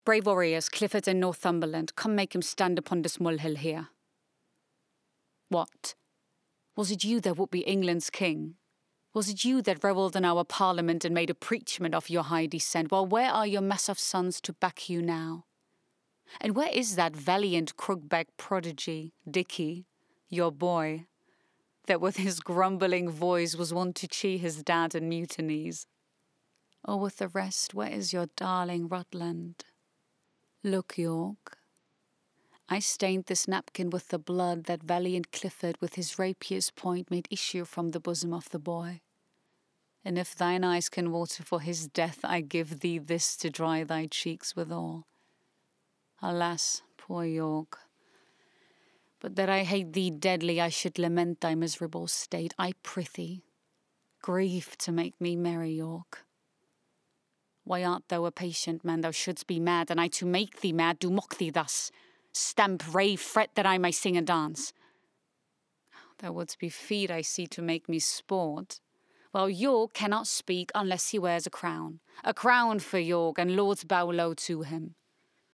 VOICE
Voice: alto
Voice Character: deep, dark, warm
German (First language), dialect: neutral
English: Fluent, accent: RP, California, Arabic, Turkish